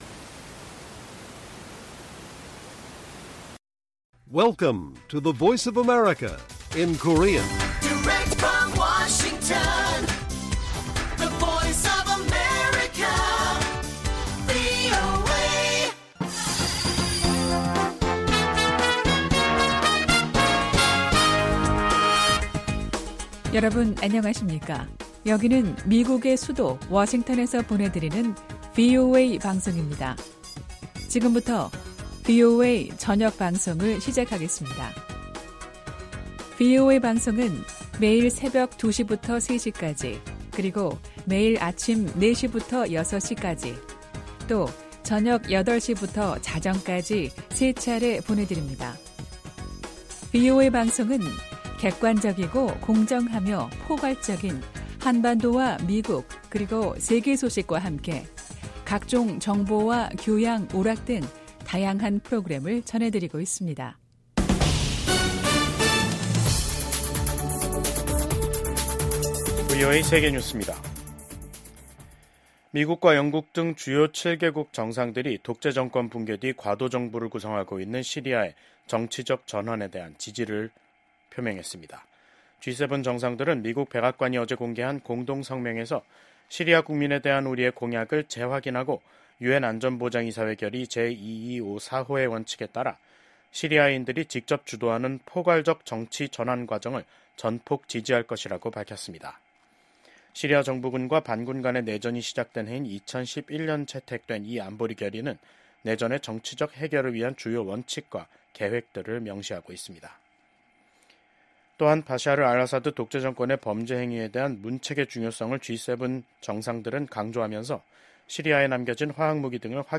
VOA 한국어 간판 뉴스 프로그램 '뉴스 투데이', 2024년 12월 13일 1부 방송입니다. 비상계엄 사태를 일으킨 윤석열 한국 대통령에 대한 탄핵소추안 2차 투표가 내일 진행됩니다. 미국의 전직 고위 관리들은 북한이 연일 한국 대통령의 비상 계엄 선포와 탄핵 정국을 보도하는 것은 한국 정부를 비난하고 미한 동맹을 약화시키려는 선전선동 목적이 크다고 진단했습니다.